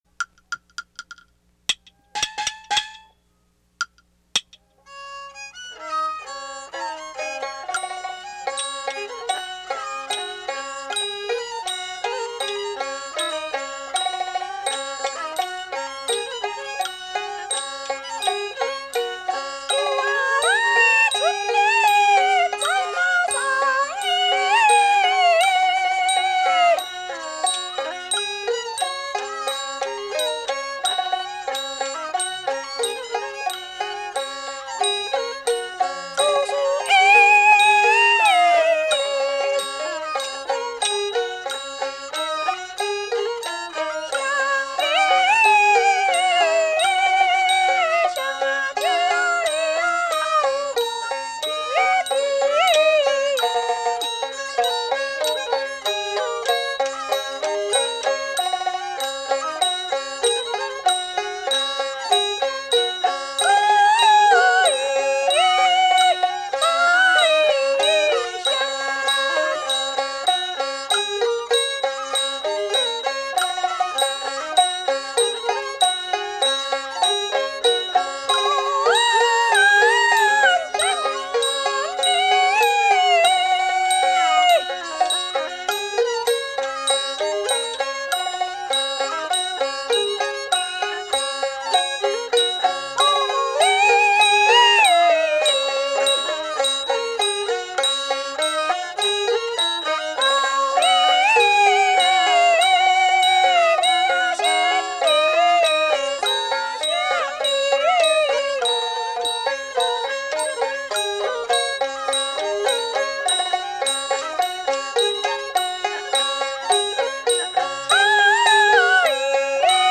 戲曲 - 羅成寫書選段3（流水） | 新北市客家文化典藏資料庫